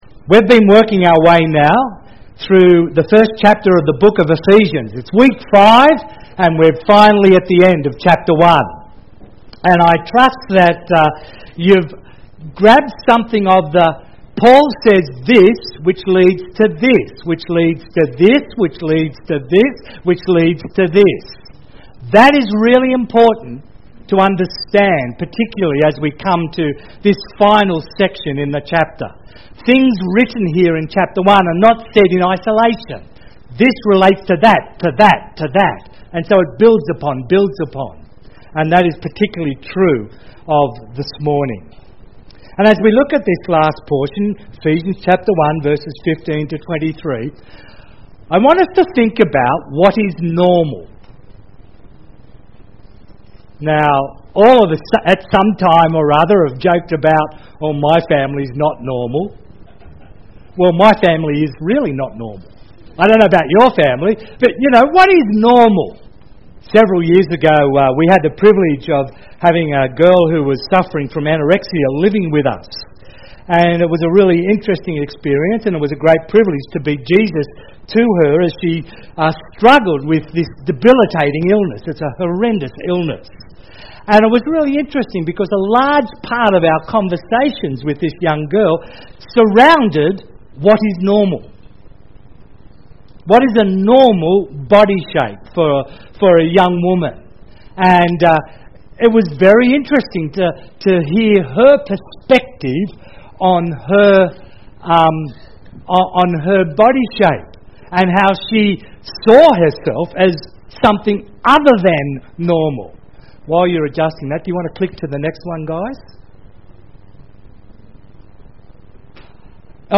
Ephesians 1:15-23 Listen to the sermon. Categories Sermon Tags ephesians